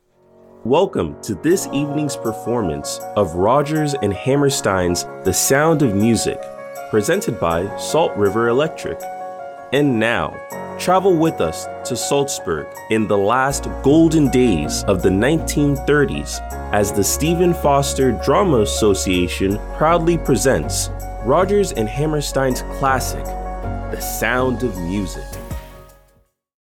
Friendly and energetic individual that brings life to all narrations!
Young Adult